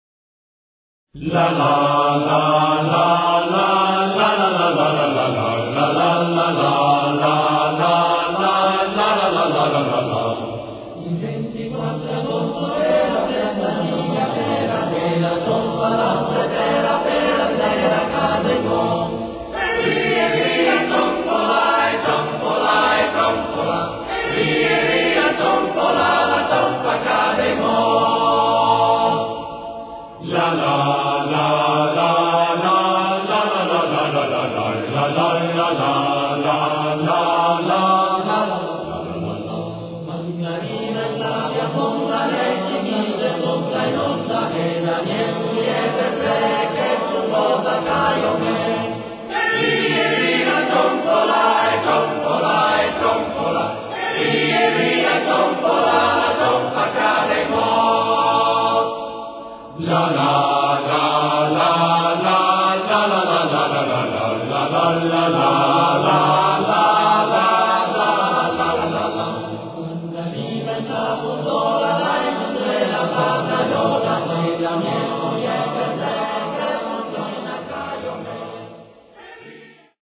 La Cionfa - Coro Stelutis
[ voci virili ]
Un’ultima considerazione: anche la melodia (fresca e briosa, anche se non eccezionale) non ha evidenti riscontri con altre melodie a me note.